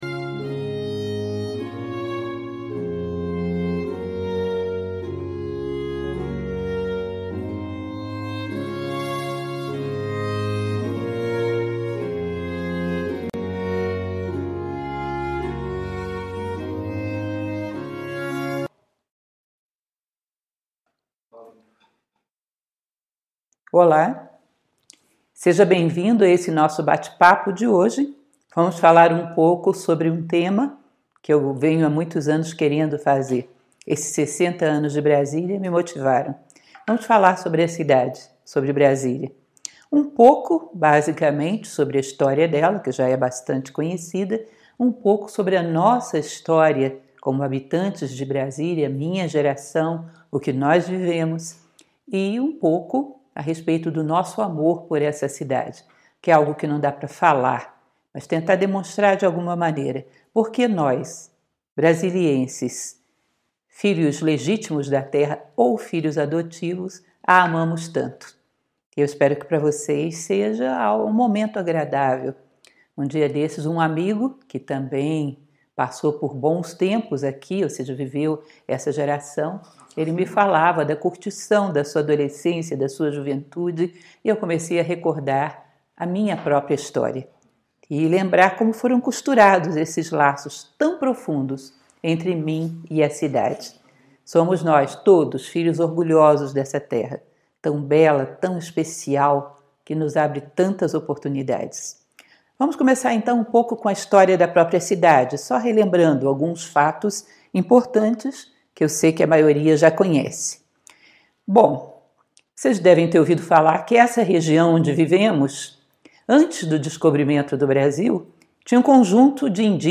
Palestra online